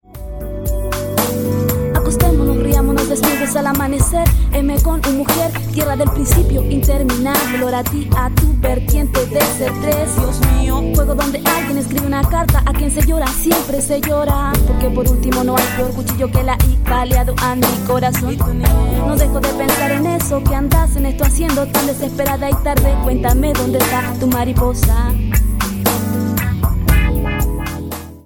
soul
hip-hop
ritmos afro-americanos
bandas femeninas